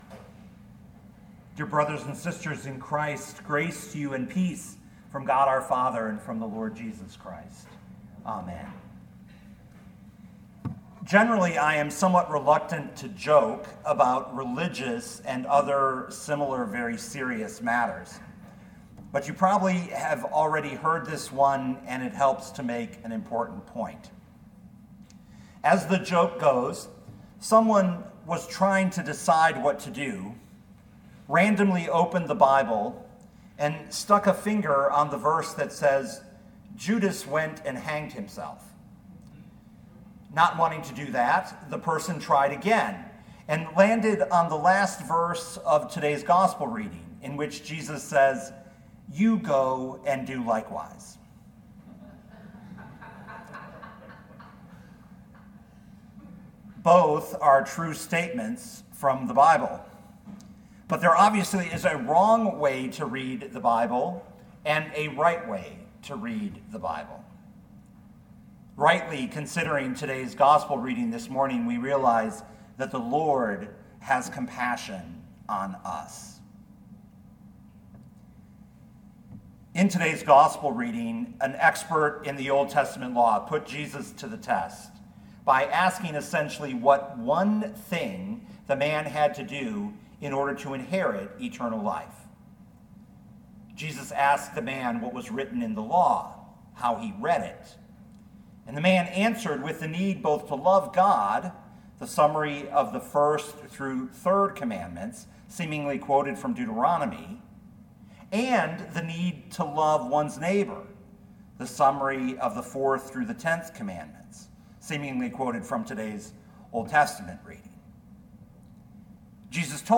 2022 Luke 10:25-37 Listen to the sermon with the player below, or, download the audio.